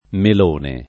mel1ne] (meno com. mellone [mell1ne]) s. m. — solo mellone nel sign. di «sciocco» (col der. mellonaggine), oggi non più com., risalente al tardo Medioevo, quando con mellone (la forma allora più com.) s’intendeva non propr. il «popone» ma un altro frutto più insipido — sim. i cogn. Melone, ‑ni, Mellone, ‑ni